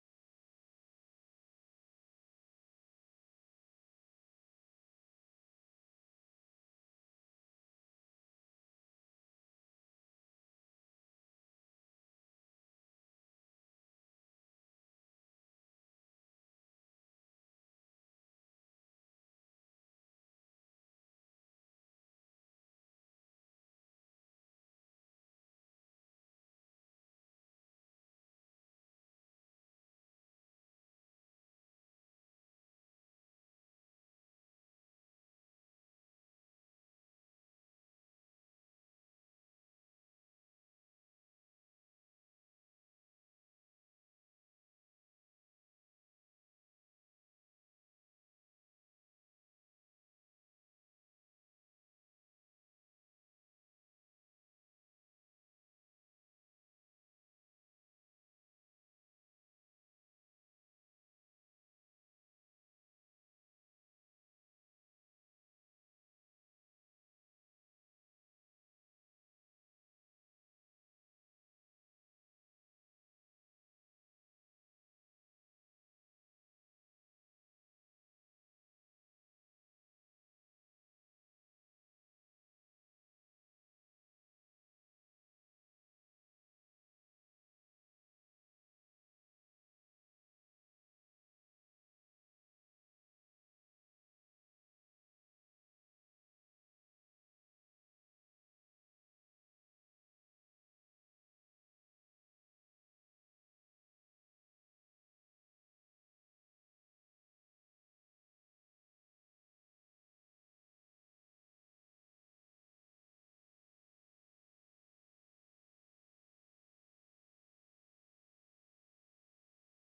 主日证道 Download Files Notes « 你在這裡作什麼？